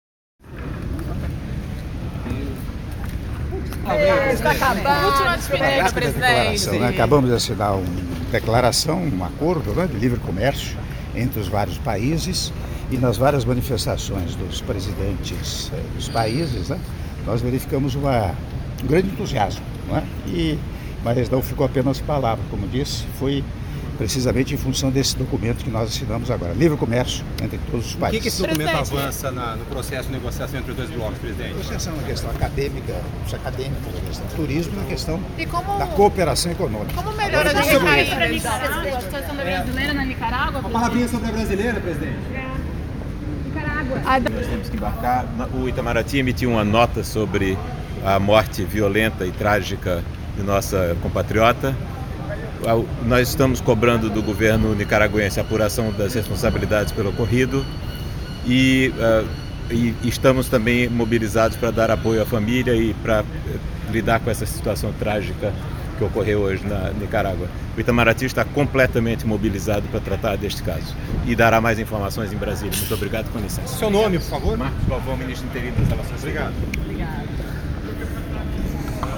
Áudio da Entrevista coletiva concedida pelo Presidente da República, após Reunião dos Chefes de Estado do Mercosul e da Aliança do Pacífico - Puerto Vallarta/México (01min19s)